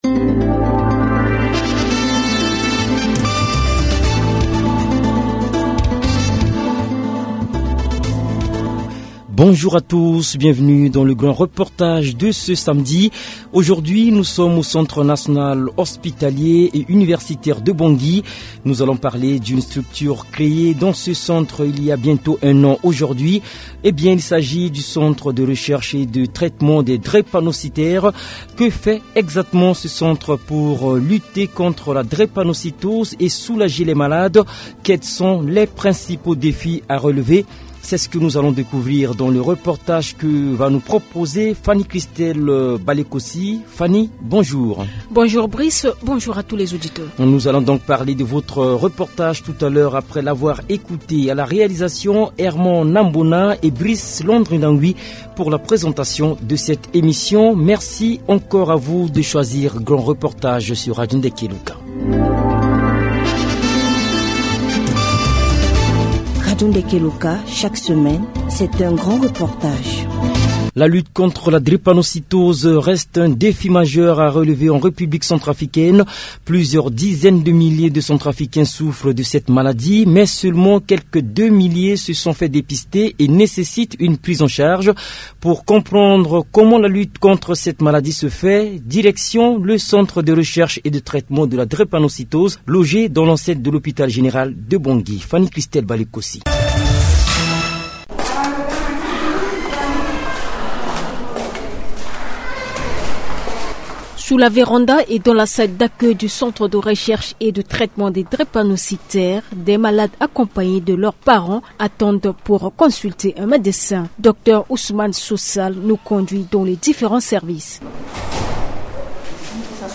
La prise en charge de la maladie pose encore problème. Radio Ndeke Luka s’est rendue au centre de recherche et de traitement de la drépanocytose à Bangui.